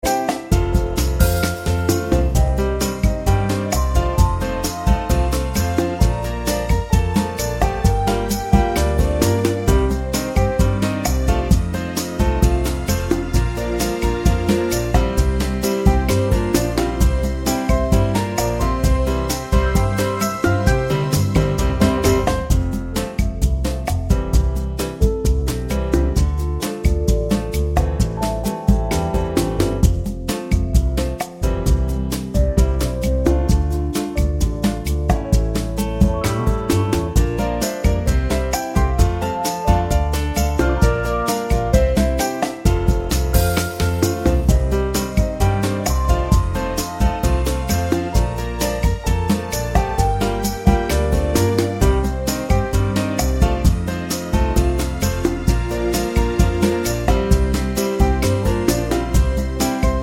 Original Female Key